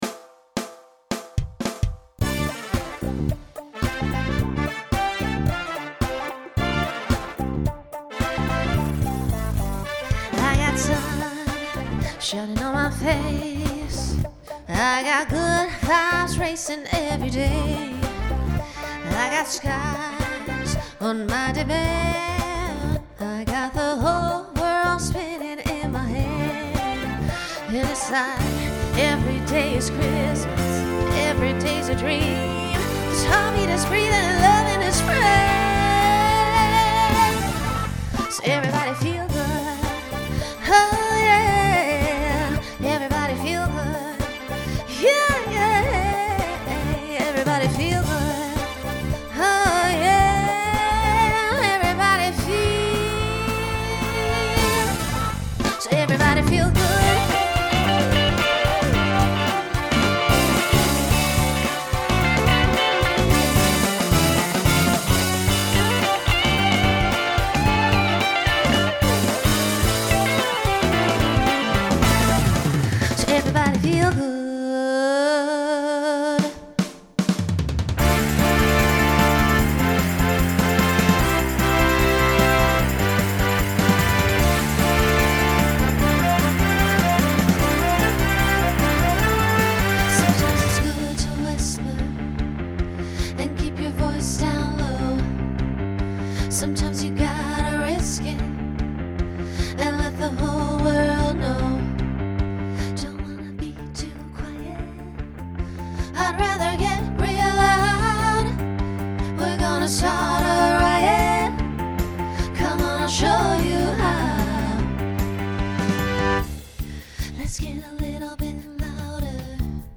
Genre Pop/Dance , Rock
Voicing SSA